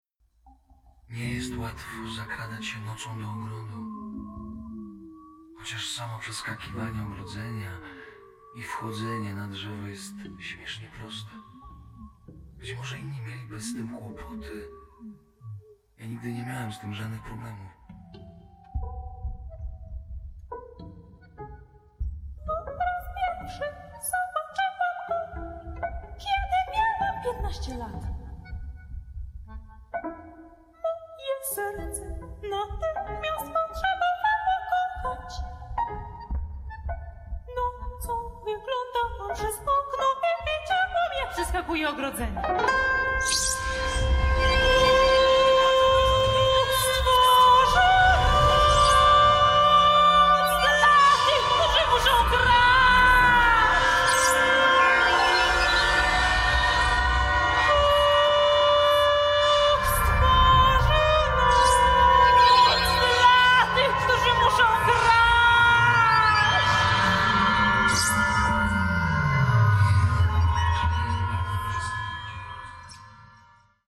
opera kameralna na głos żeński, aktora i i zespół